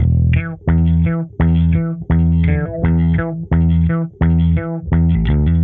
Index of /musicradar/dusty-funk-samples/Bass/85bpm